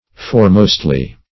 Search Result for " foremostly" : The Collaborative International Dictionary of English v.0.48: Foremostly \Fore"most`ly\, adv. In the foremost place or order; among the foremost.